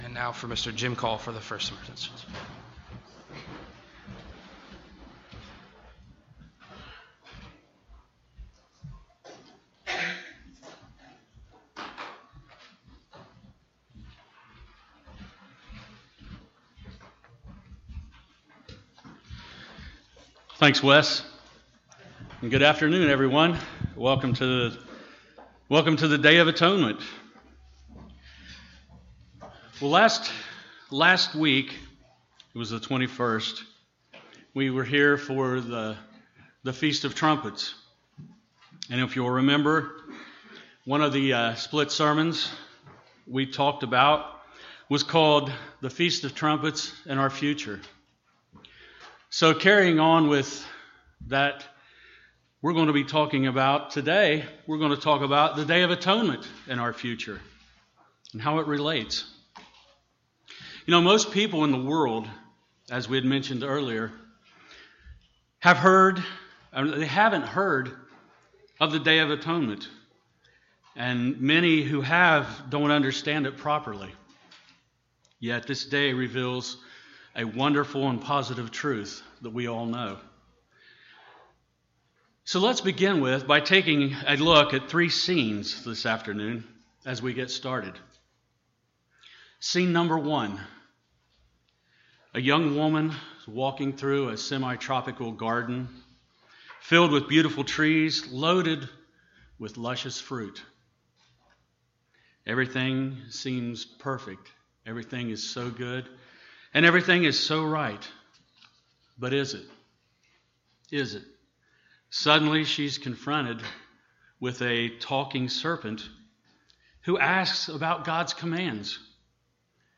In this sermon we will cover the meaning of Atonement and how it relates to each of us.
Given in Portsmouth, OH